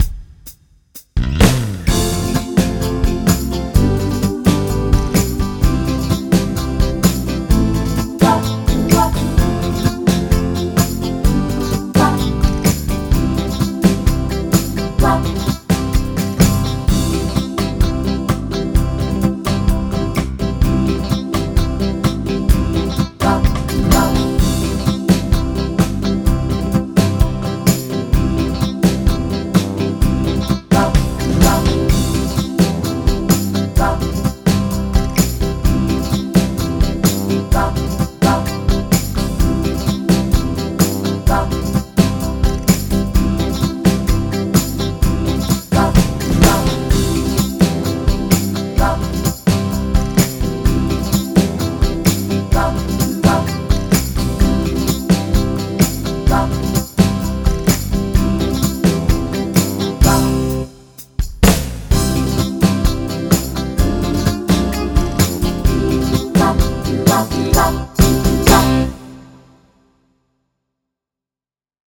Egy SHAKIN OLDIES stílus még,ugyanezen eszközökkel aztán nem is untatlak többel benneteket: